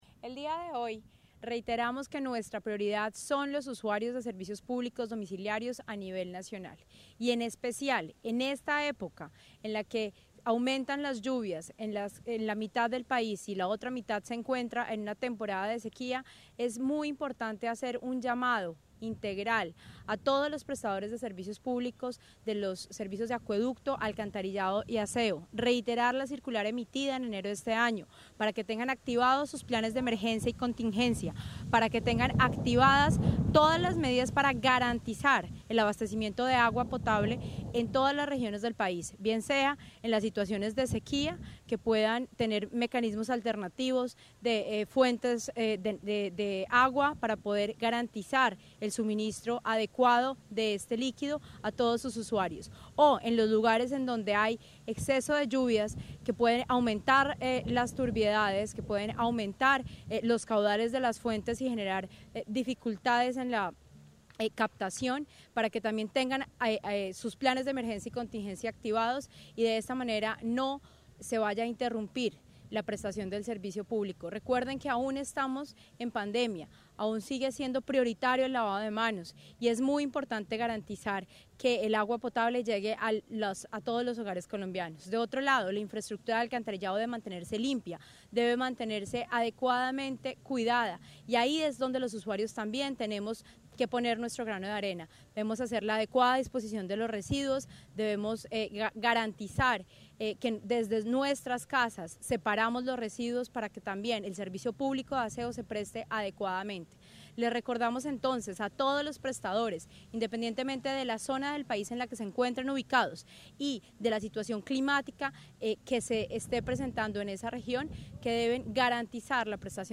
Declaraciones de la superintendente Natasha Avendaño García